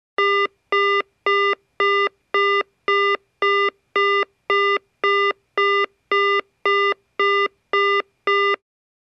Звук женского голоса оператора Абонент временно недоступен перезвоните позже